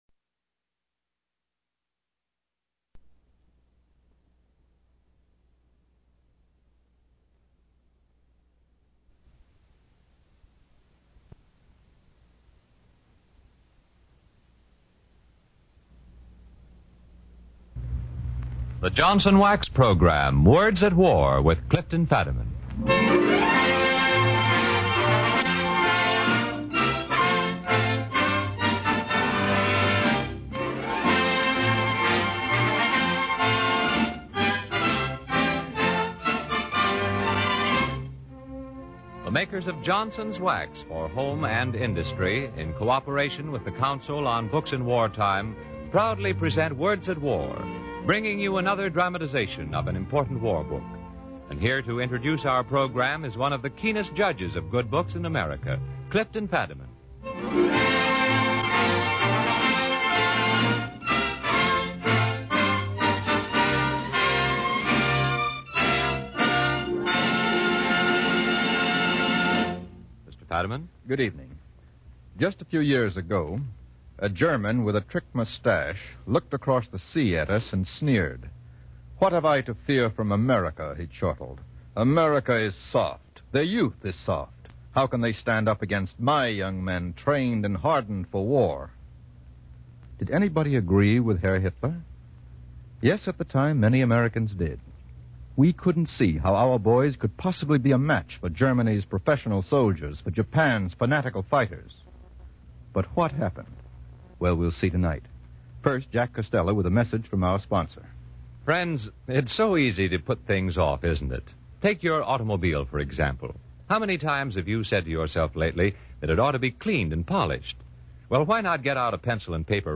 Words At War, the series that brings you radio versions of the leading war book another adaptation of an important war book, “One-Man Air Force”.